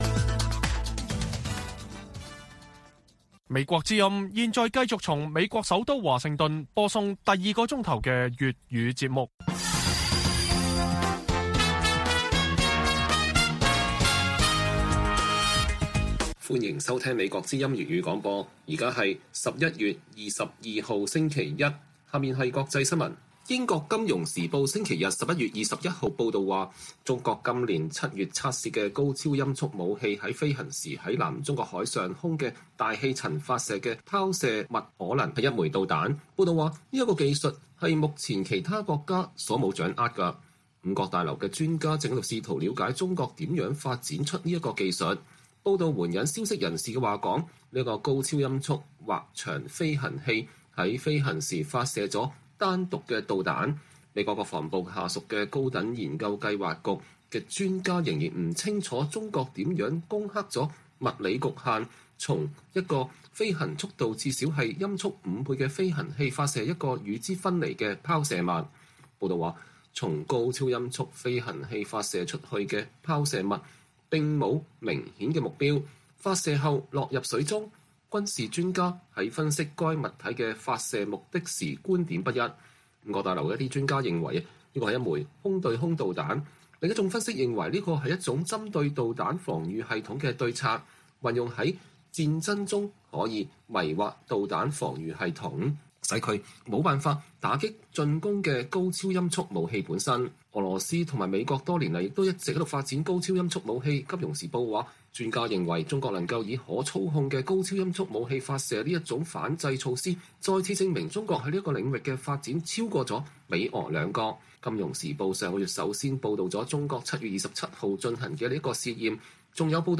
粵語新聞 晚上10-11點: 如果美國對北京冬奧的外交抵制將意味著甚麼？